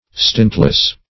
Stintless \Stint"less\, a.